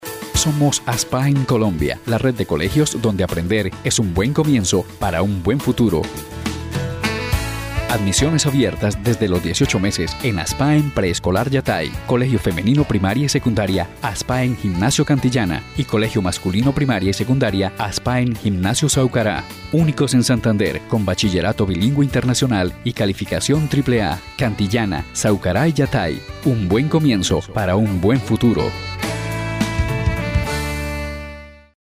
Kein Dialekt
Sprechprobe: eLearning (Muttersprache):
Professional voice for recording Videos for television, youtube and other social networks Professional voice for educational and explanatory content elearning Professional voice for radio and television commercials Professional Voice for PBX - IVR Telephone or Audio response systems Professional audio recording and editing